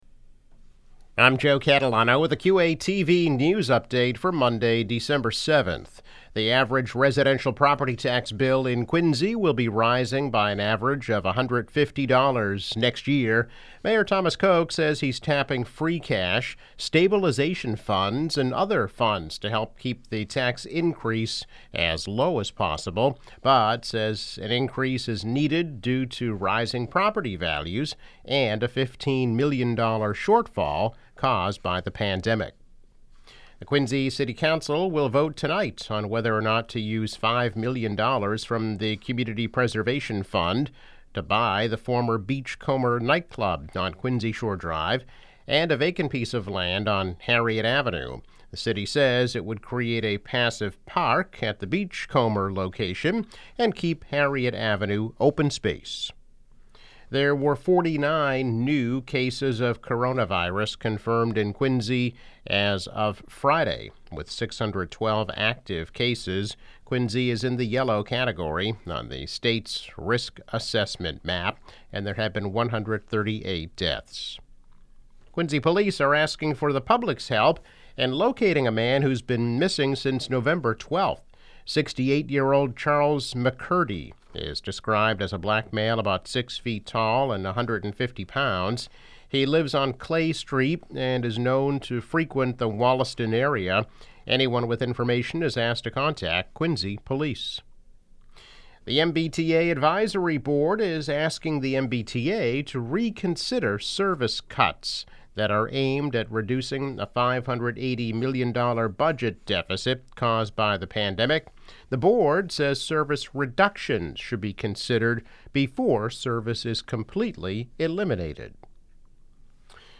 News Update - December 7, 2020